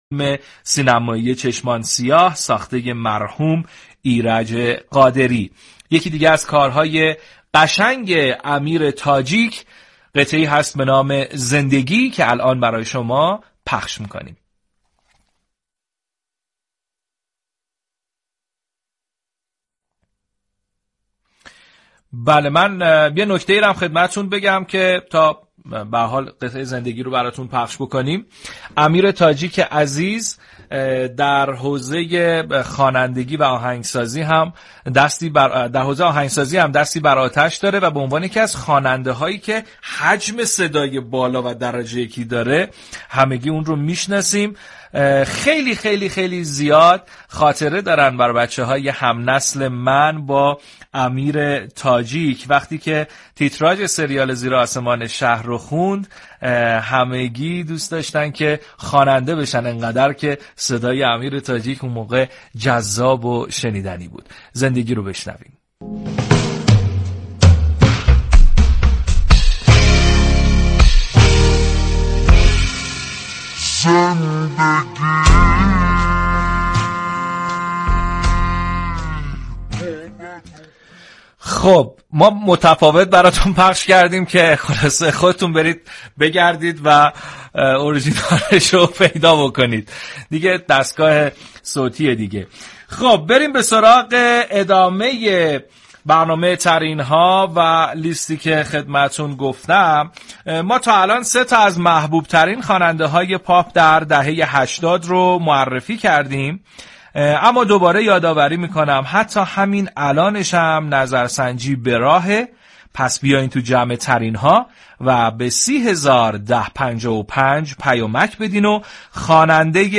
امیر تاجیك در گفتگو با رادیو صبا از راز ماندگاری موسیقی گفت